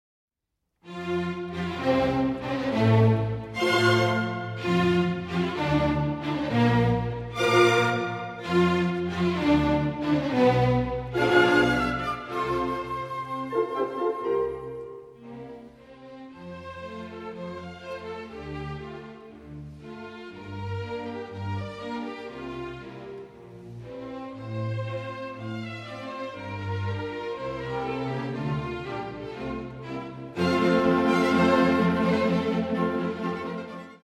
Allegro 8:45